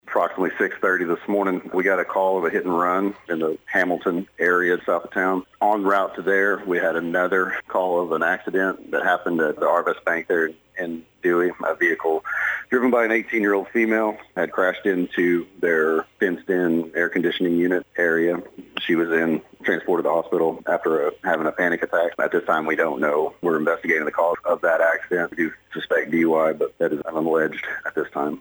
Police Chief Jimmy Gray said,